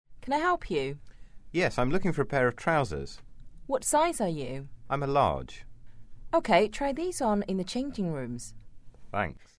英语初学者口语对话第56集：我想买一条大号的裤子
english_24_dialogue_2.mp3